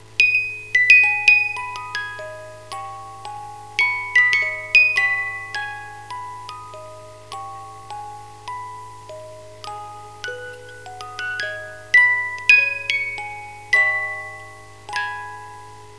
carillon